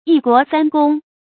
注音：ㄧ ㄍㄨㄛˊ ㄙㄢ ㄍㄨㄙ
一國三公的讀法